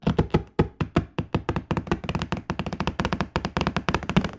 Её кожа натягивается при полном надувании (имитация)